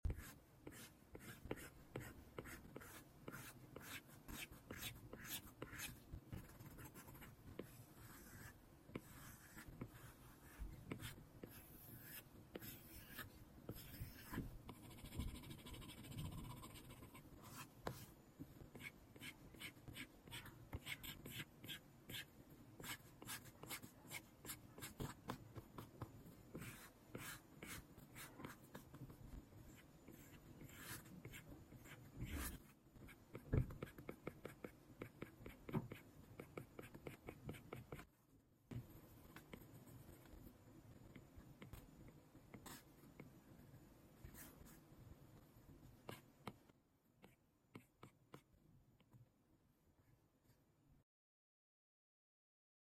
Drawing white noise, enjoy the sound effects free download
enjoy the Mp3 Sound Effect Drawing white noise, enjoy the rustling sound.